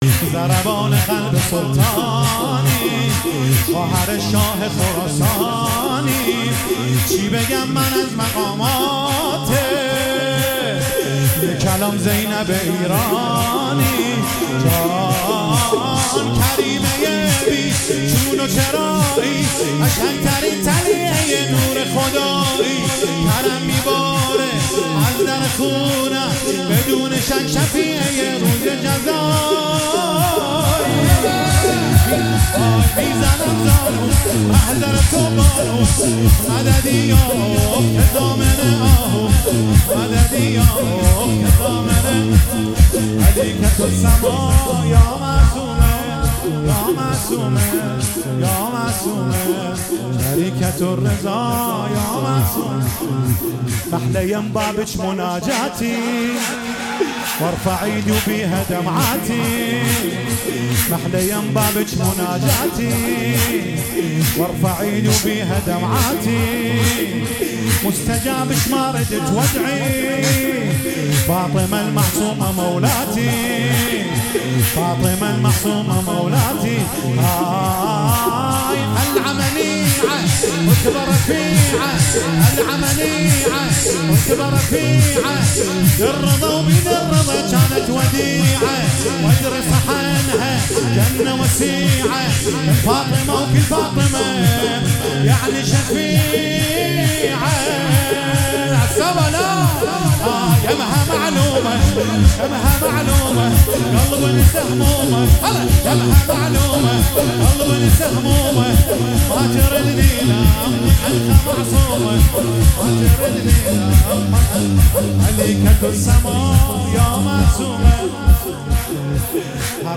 مولودی خوانی
میلاد حضرت معصومه سلام الله علیها 1402